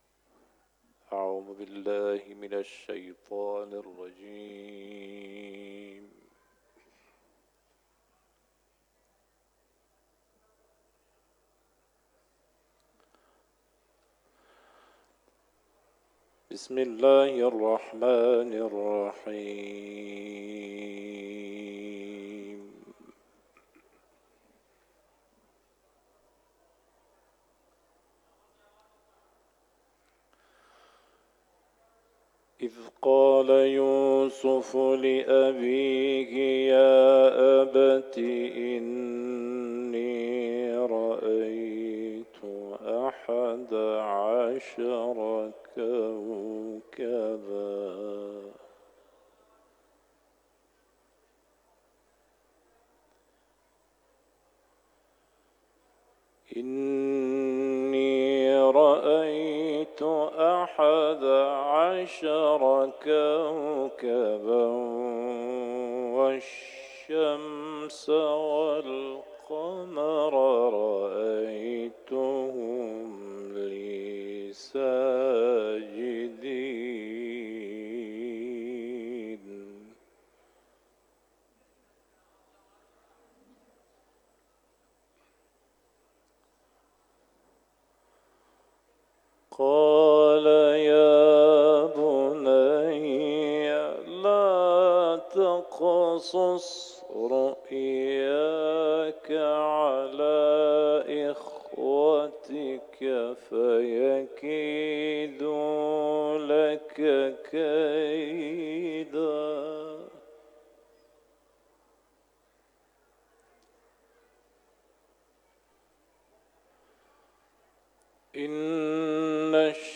تلاوت
سوره قصص ، حرم مطهر رضوی